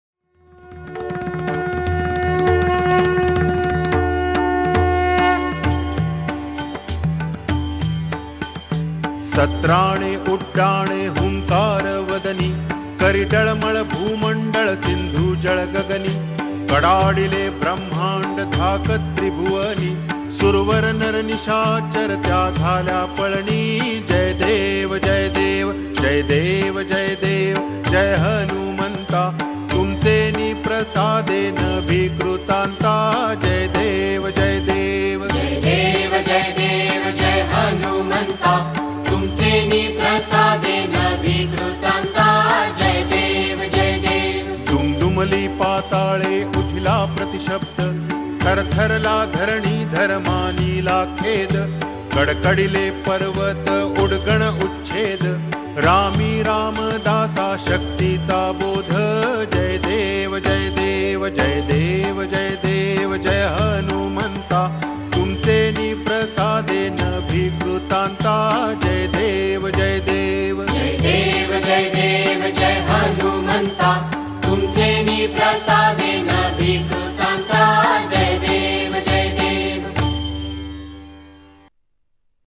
Marathi Aarti